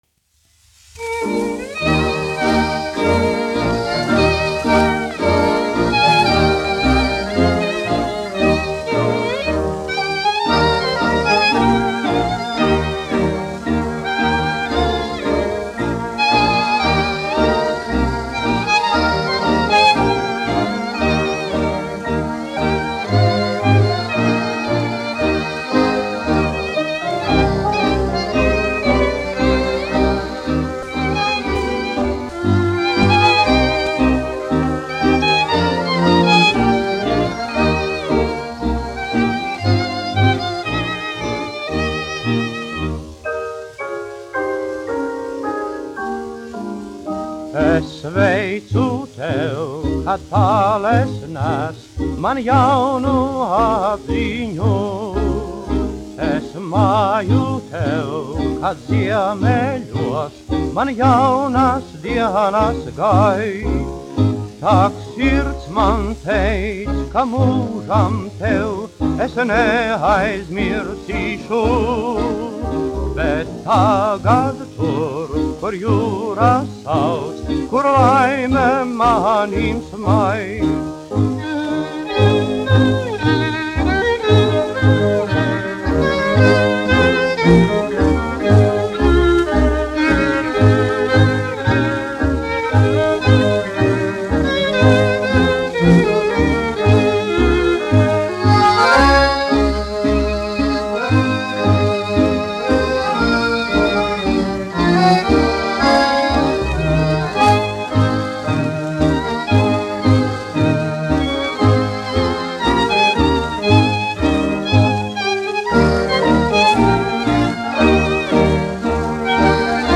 1 skpl. : analogs, 78 apgr/min, mono ; 25 cm
Kinomūzika
Skaņuplate
Latvijas vēsturiskie šellaka skaņuplašu ieraksti (Kolekcija)